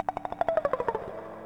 34c-fx-68Dmin.wav